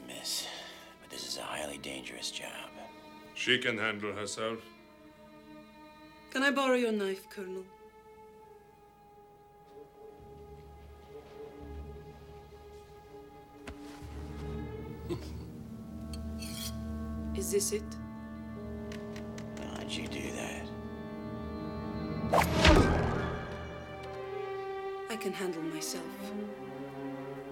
Memorable Dialog